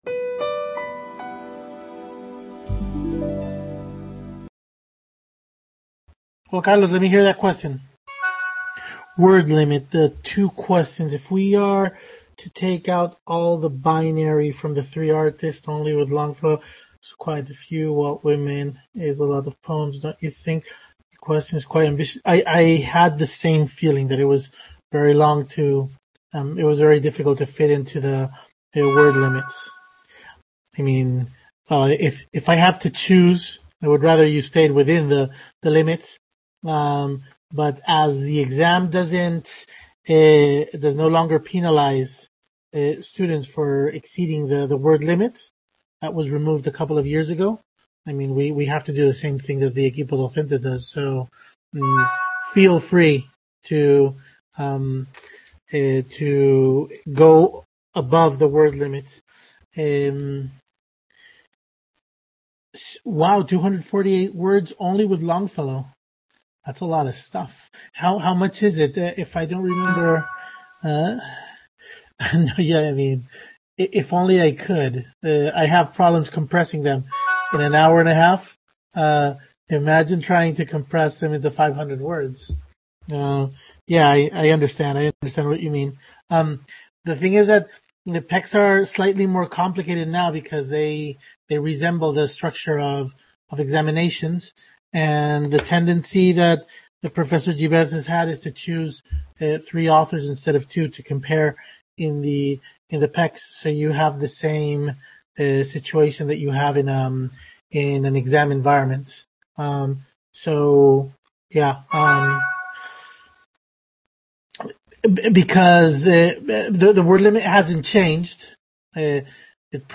Webconference held on April 1st 2020